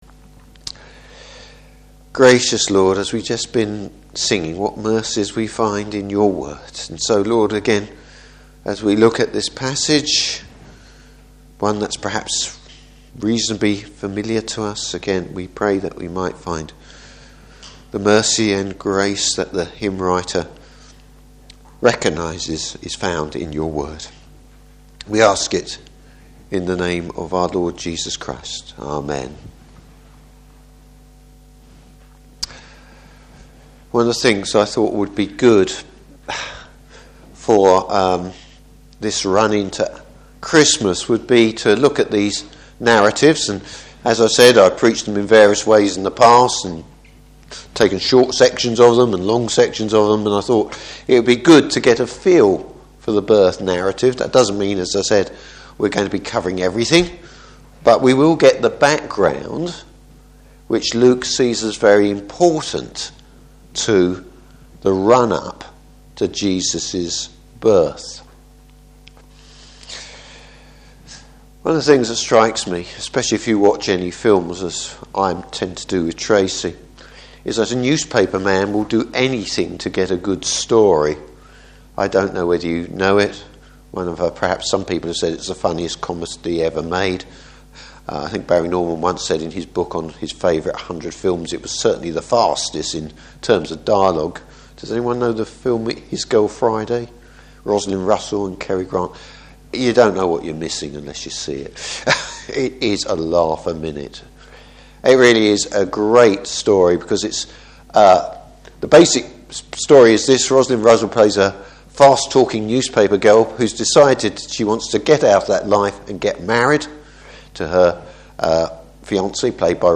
Service Type: Evening Service Luke goes right back to the beginning.